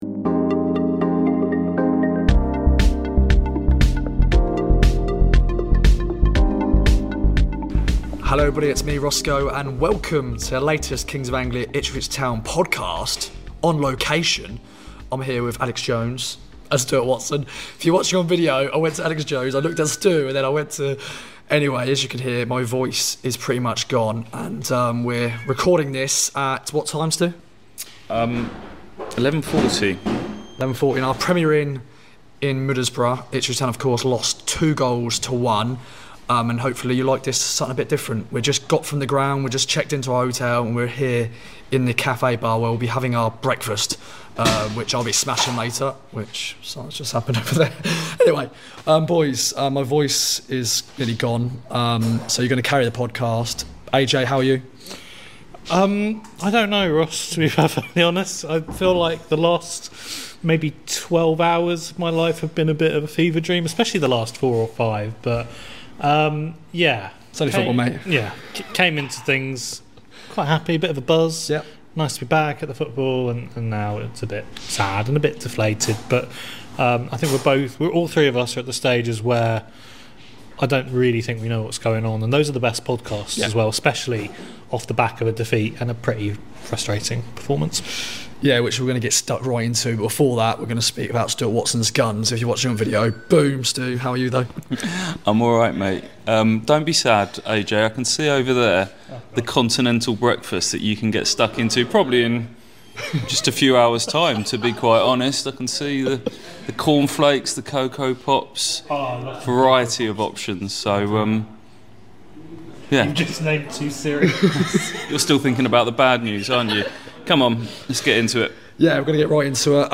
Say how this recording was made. Recording from the lobby of their Premier Inn down the road from the Riverside Stadium, the boys look back on what went wrong for Town up on Teesside. There’s also some late-night shenanigans in a rough-and-ready podcast recorded just hours after the full-time whistle.